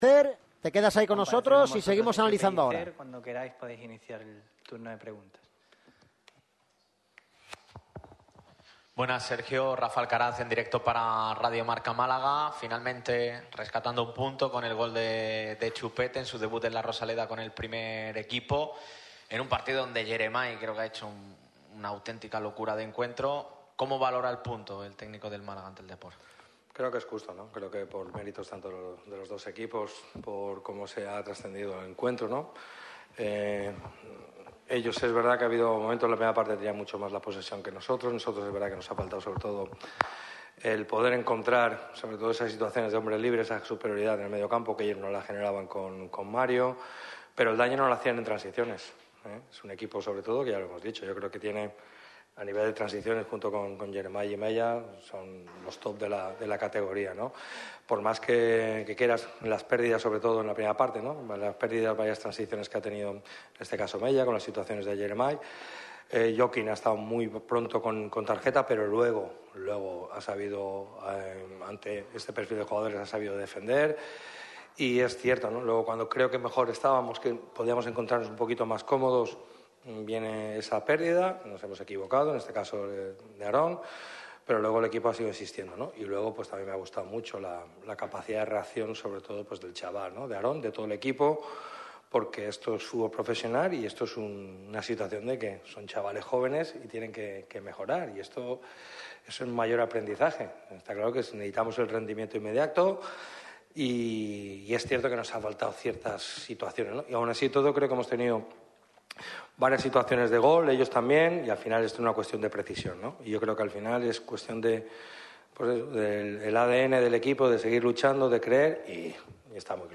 El entrenador del Málaga CF, Sergio Pellicer, ha comparecido en rueda de prensa tras el empate cosechado ante el RC Deportivo de La Coruña. Chupete salvó un punto para los malaguistas y el de Nules ha hablado sobre el cordobés y ha realizado un análisis del partido, dejando varios titulares destacados.